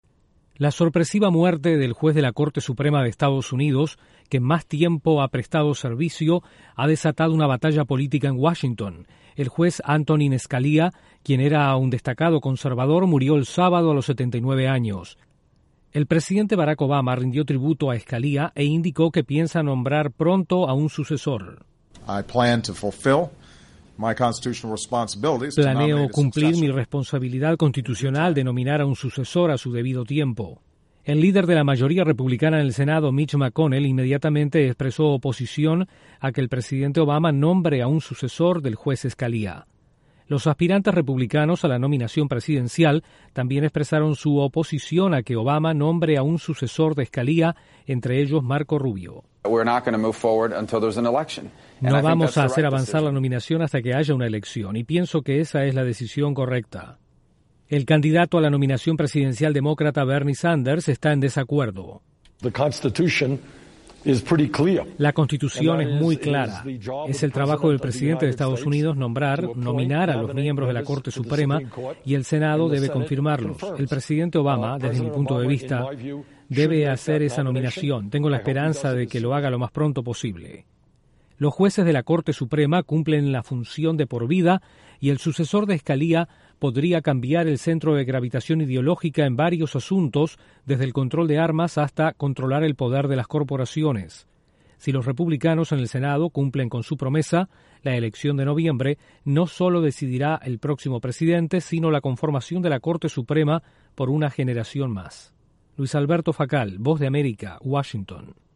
La muerte de un juez de la Corte Suprema de EE.UU. desata un enfrentamiento político. Desde la Voz de América en Washington informa